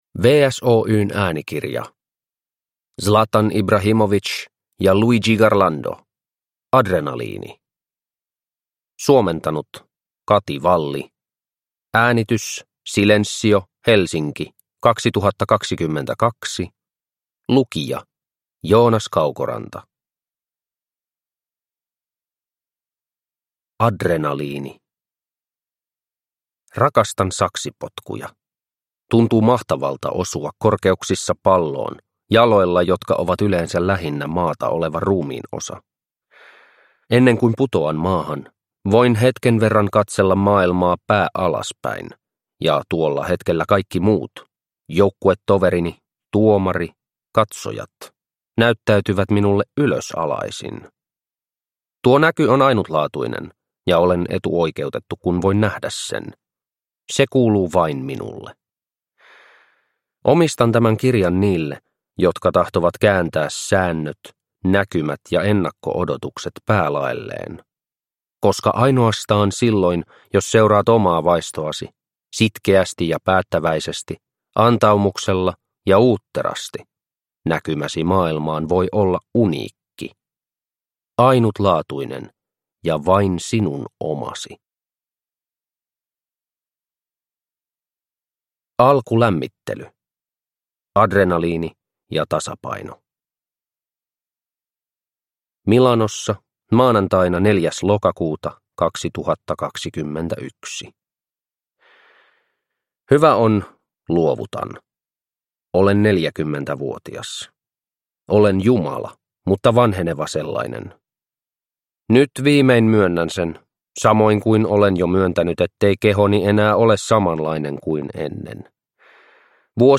Adrenaliini – Ljudbok – Laddas ner